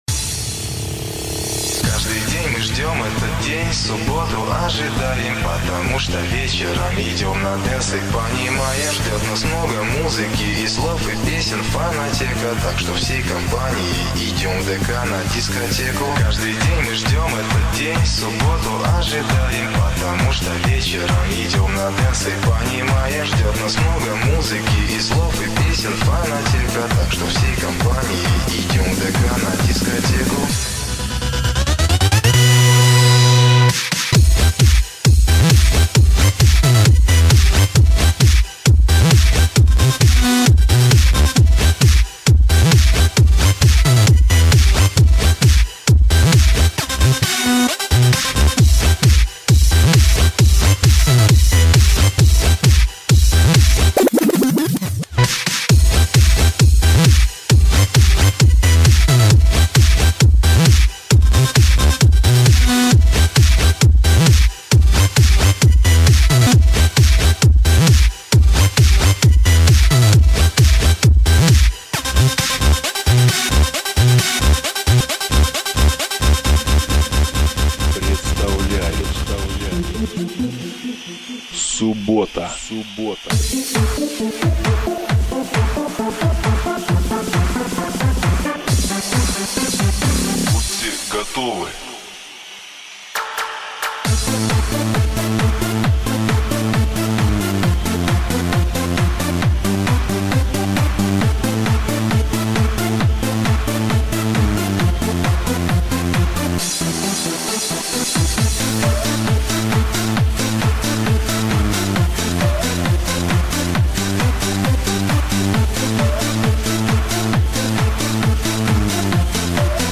Жанр: Сlub